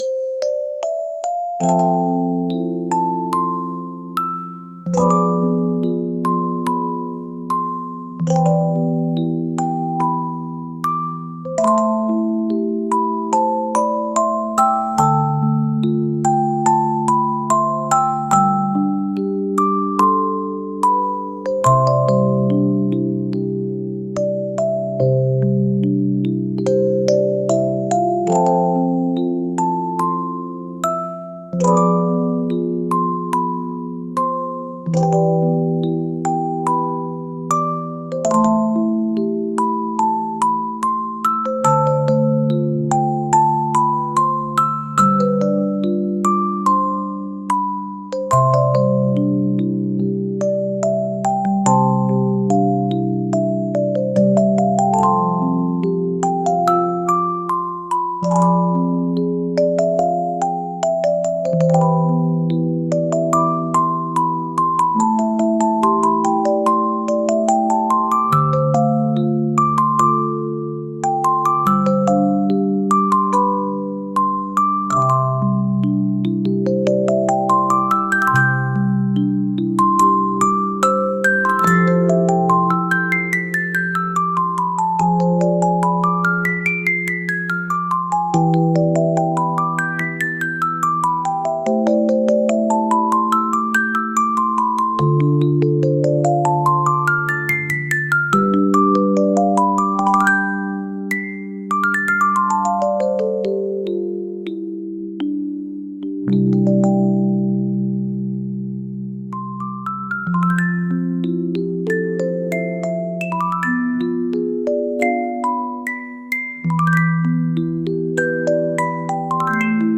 激しくもないゆったりした音楽です。